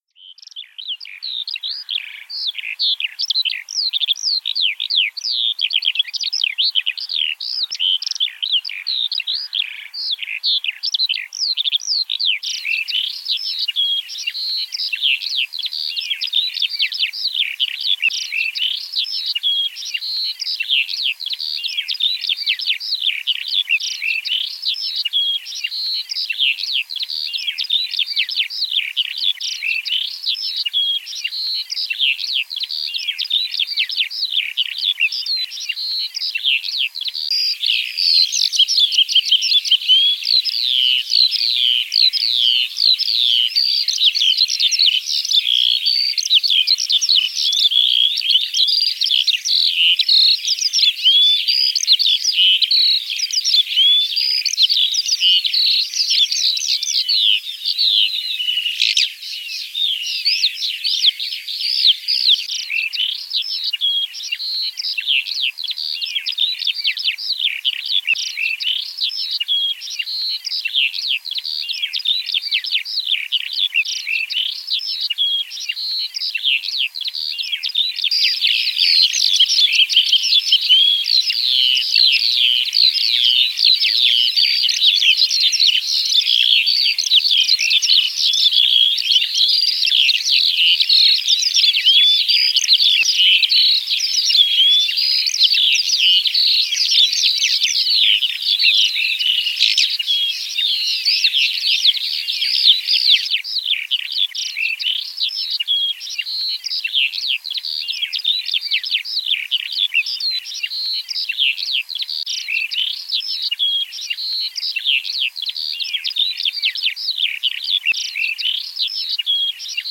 [*] 👉 Ở Việt Nam, chiền chiện hay sơn ca là loài chim nổi tiếng vì bay cao rồi hót líu lo rất dài và vang vang trong không trung.
– Tên tiếng Anh: Oriental Skylark (có nhiều ở Việt Nam).
– Điểm đặc trưng [signature/dấu ấn]: vừa bay vút lên cao vừa hót liên tục.
– Tiếng hót oang oang, vang dội, và trong trẻo pha nhiều giai điệu trầm bổng, nghe là nhận ra ngay nó chính là “chiền chiện/sơn ca”.